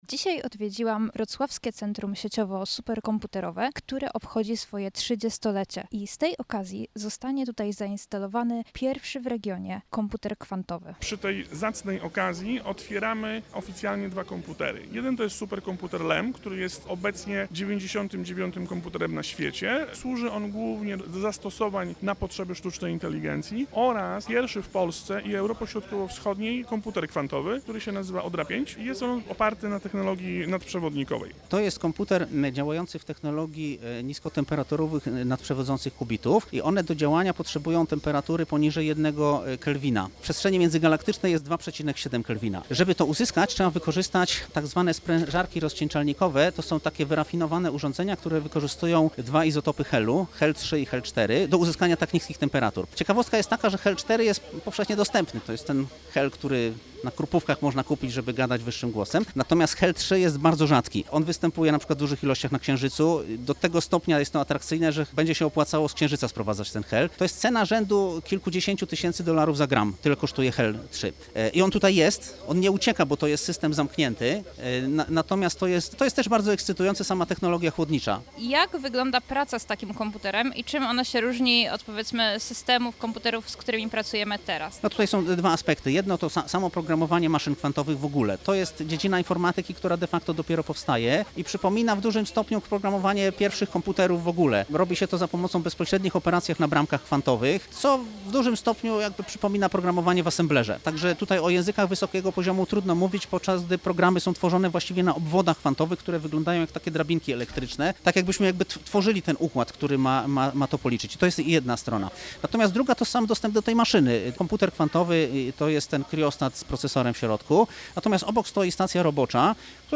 Podczas wydarzenia uruchomiono dwa komputery: Superkomputer Lem oraz pierwszy w Europie Środkowo Wschodniej komputer kwantowy wykorzystujący technologię kubitów nadprzewodzących w niskiej temperaturze Odra 5. Rozmawialiśmy z przedstawicielami uczelni o tym, jak działa komputer kwantowy oraz jakie możliwości przyniesie zarówno pracownikom, jak i studentom.